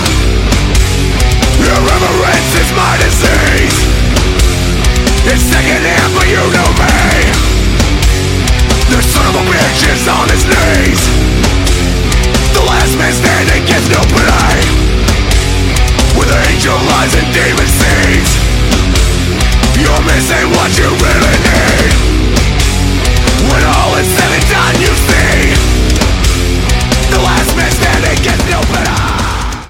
• Качество: 192, Stereo
альтернативным металлом